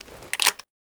load.ogg.bak